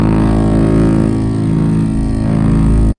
A sustained laser beam with steady hum, energy crackle, and oscillating pitch